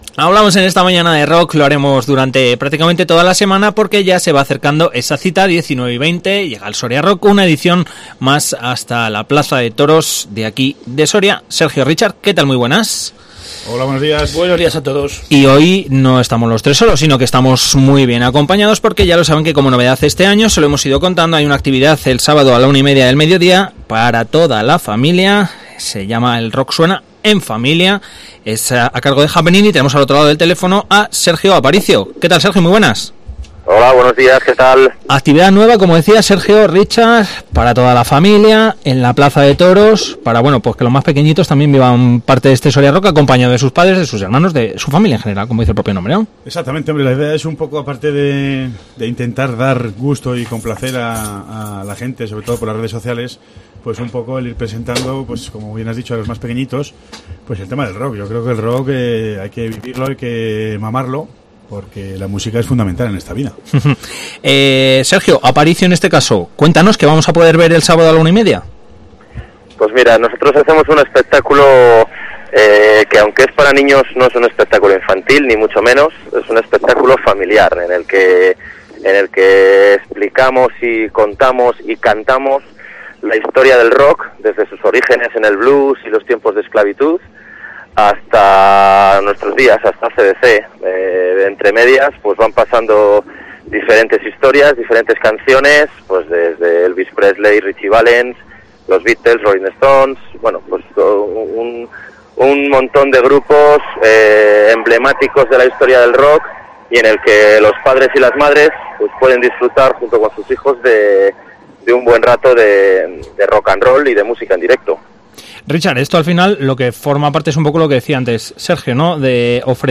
ENTREVISTA El Rock suena en familia. Soria Rock 2019.